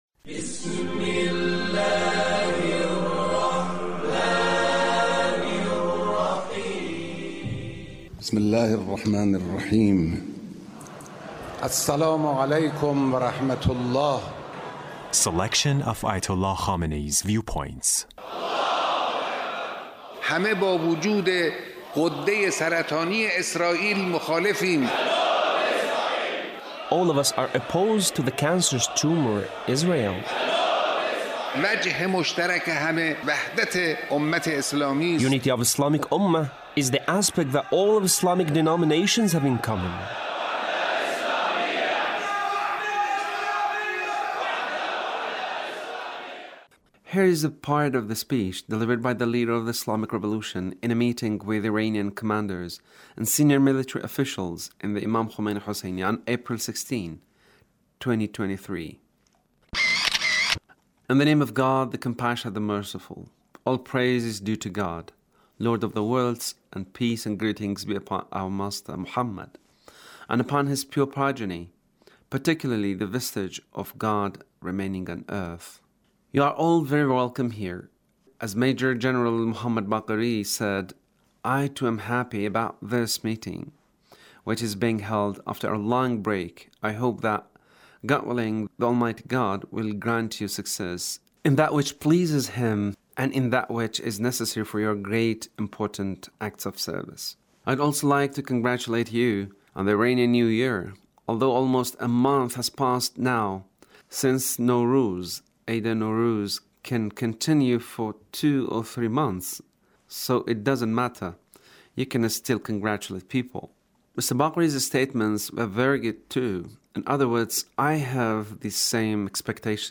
Leader's Speech with University Student